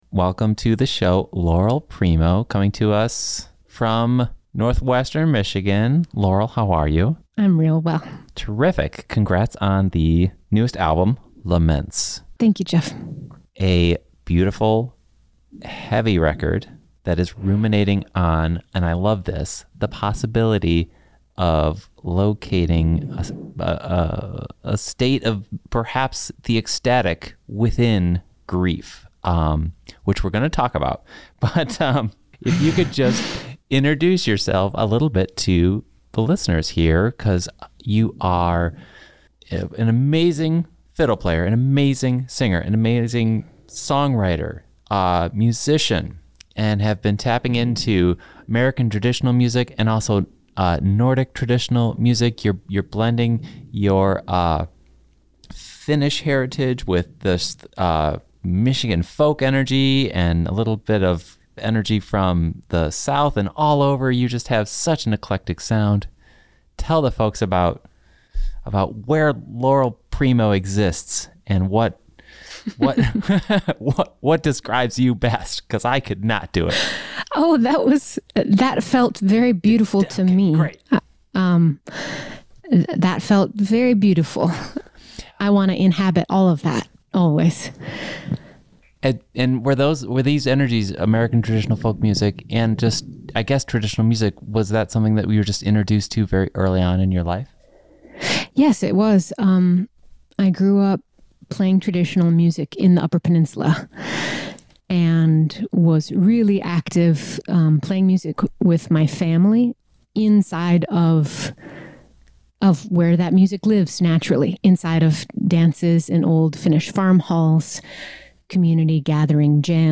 This is an extended interview from MI Local